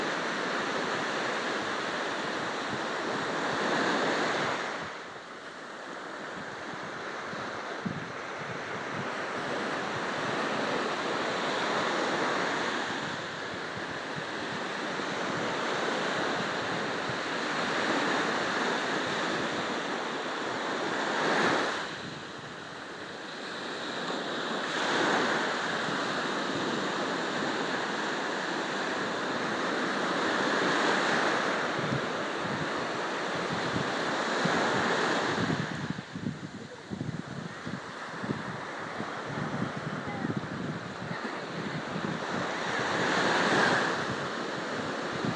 Indian Ocean
Batticaloa District, East Sri Lanka